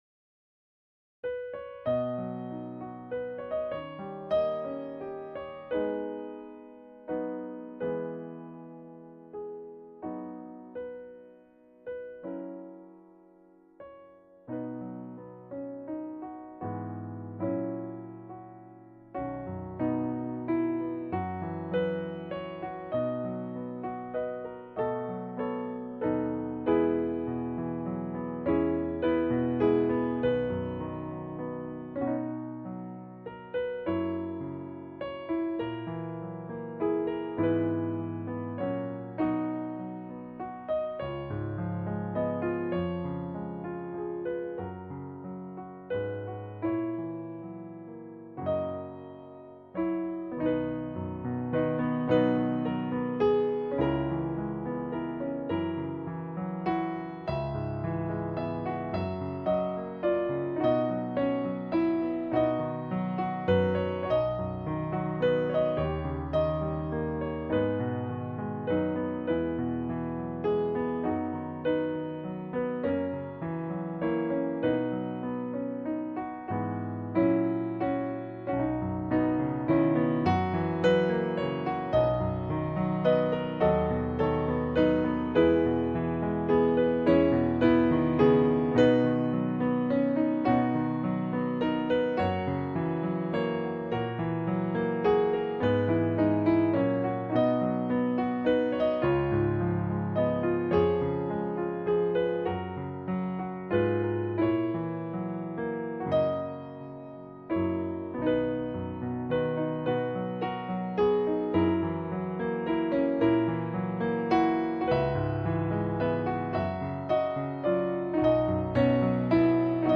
In-The-Garden-piano-hymn-instrumental-with-lyrics-VDownloader.mp3